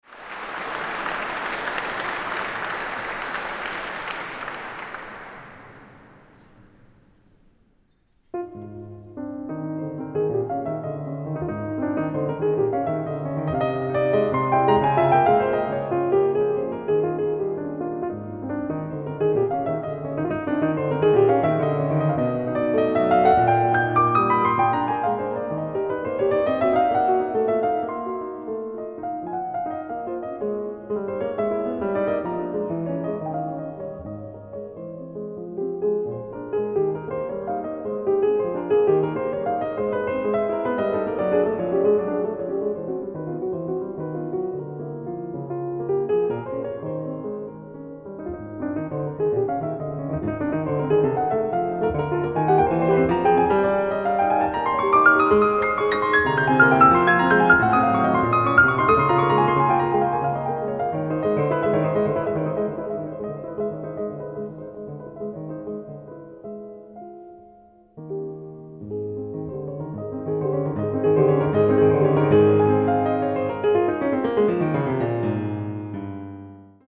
Piano Live Concert Recordings